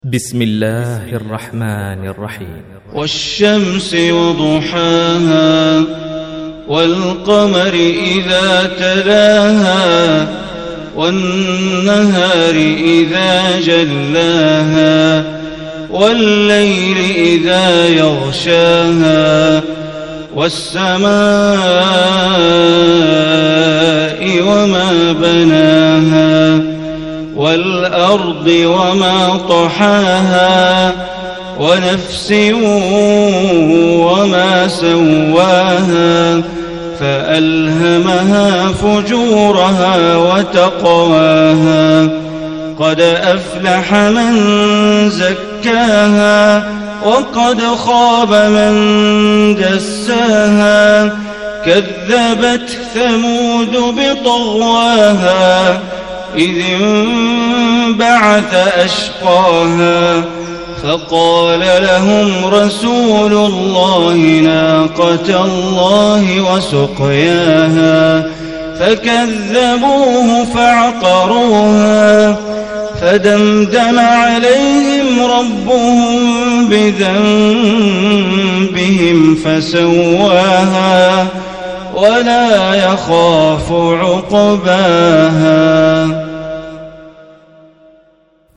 Surah Ash Shams, is 91 surah of Holy Quran. Listen or play online mp3 tilawat/ recitation in Arabic in beautiful voice of Sheikh Bandar Baleela.